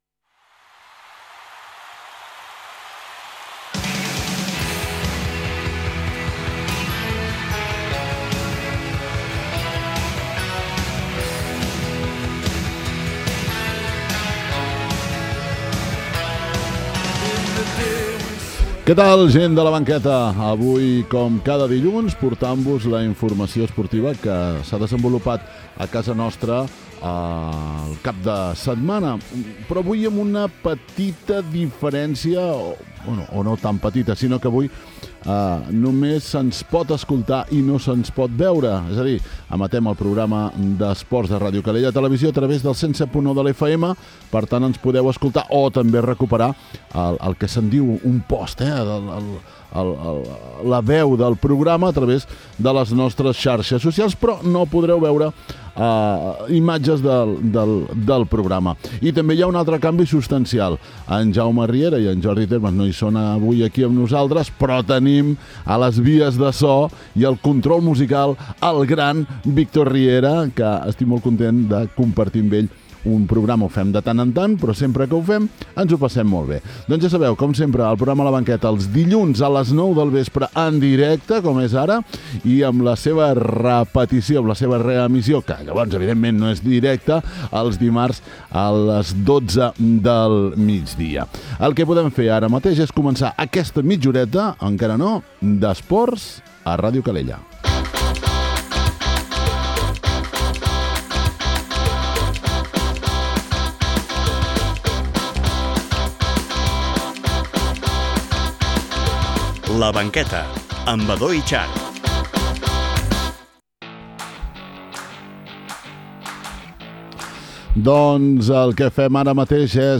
A La Banqueta amb menys de trenta minuts donem un cop d’ull als resultats dels nostres primers equips i els actes esportius destacats del cap de setmana. Avui comentem i escoltem la veu dels protagonistes de la primera edició de la cronoescalada a La Nova.